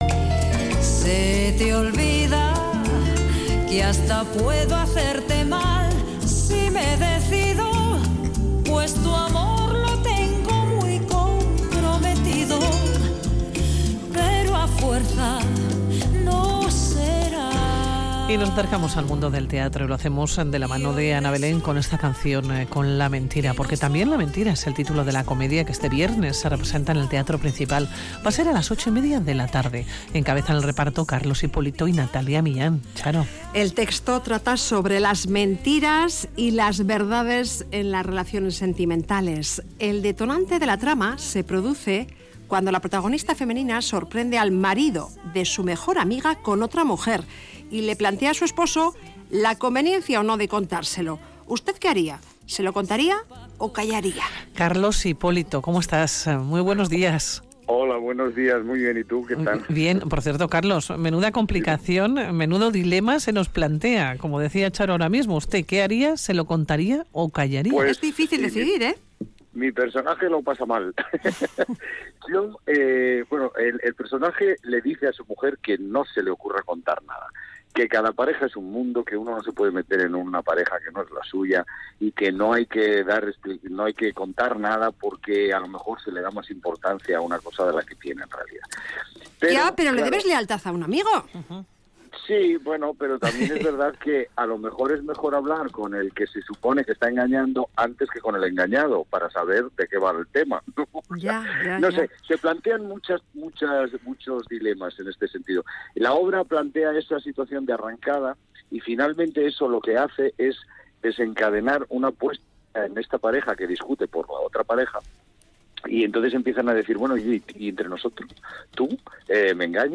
Audio: Radio Vitoria| "La mentira", comedia que este viernes se representa en el Teatro Principal. Hablamos con uno de sus protagonistas, Carlos Hipólito.